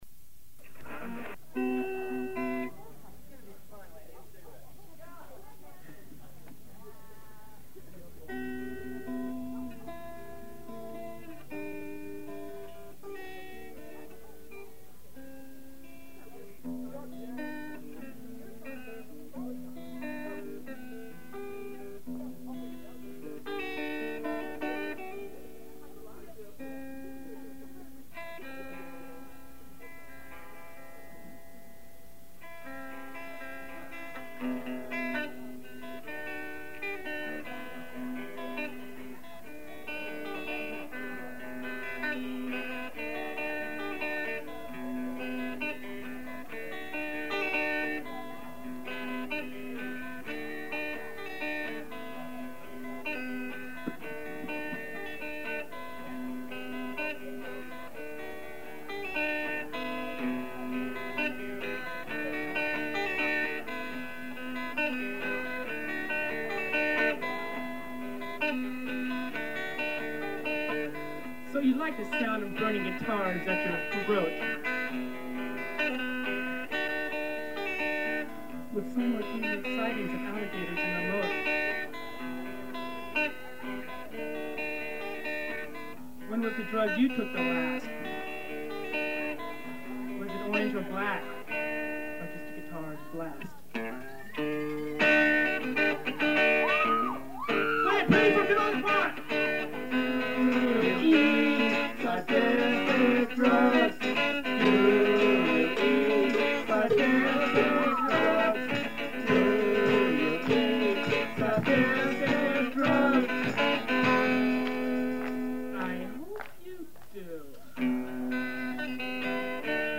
Next came PJ & CA, who played a humorous and rough 30 minute set of originals and covers, including the Canker Sore penned "Sadistic Drugs" and the Maggot/Pancreas anthem "Legalize Suicide".